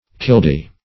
killdee - definition of killdee - synonyms, pronunciation, spelling from Free Dictionary
Killdee \Kill"dee`\, Killdeer \Kill"deer`\, n. [So named from